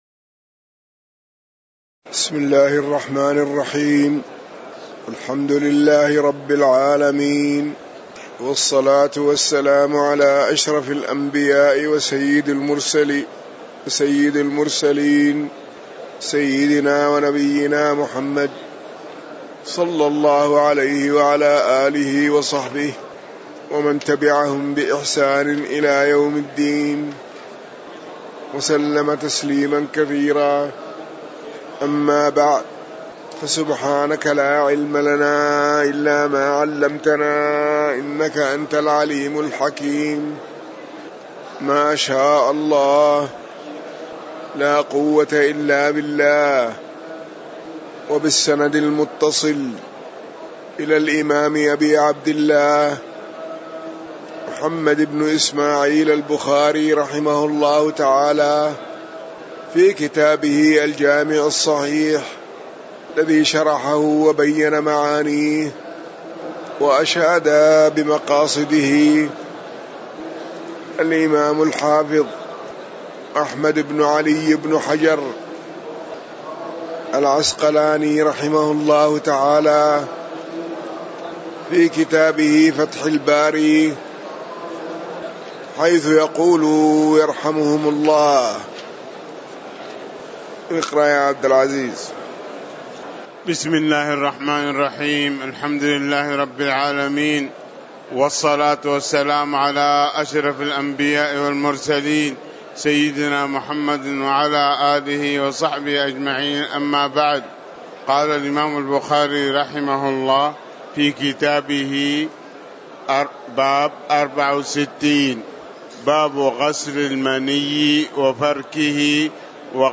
تاريخ النشر ٤ ربيع الأول ١٤٤٠ هـ المكان: المسجد النبوي الشيخ